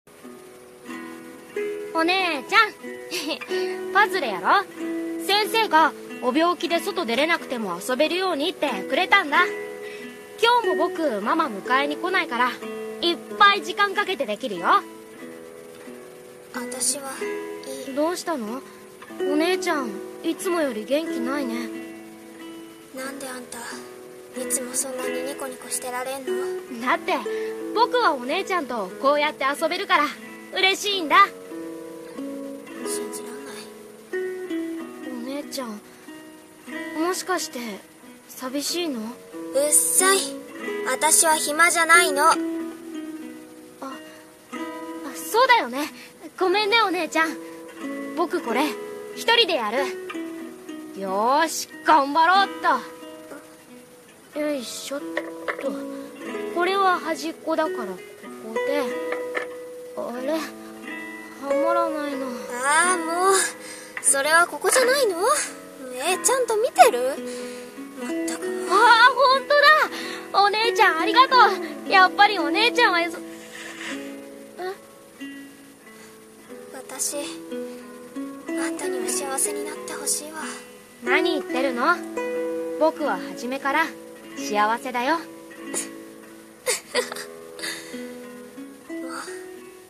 【コラボ声劇】哀しきふたり。